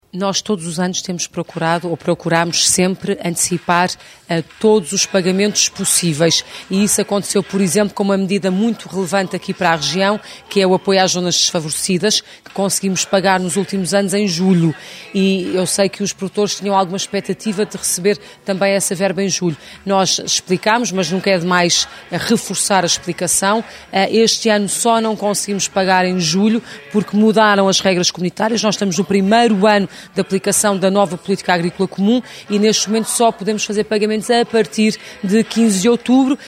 Declarações da Ministra da Agricultura, Assunção Cristas, que sexta-feira falou em Macedo de Cavaleiros.